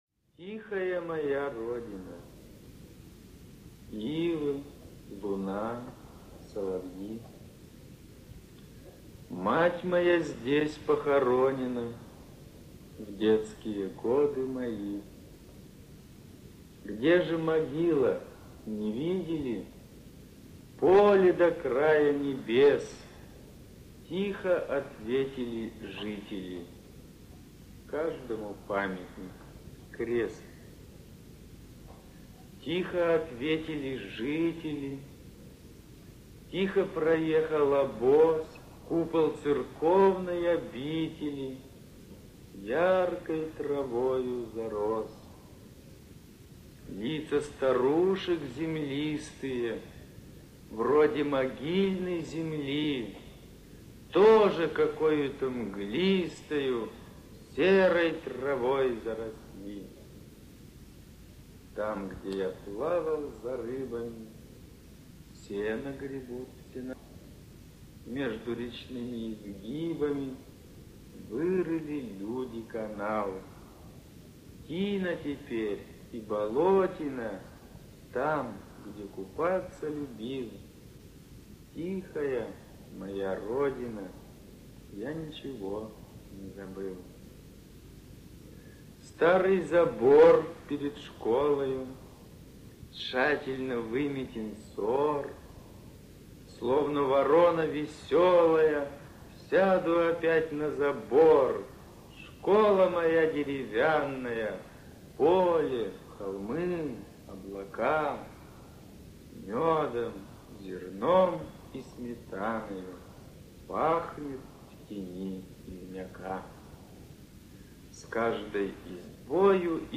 tihaya-moya-rodina-rubtsov-nikolaĭ(chitaet-avtor).mp3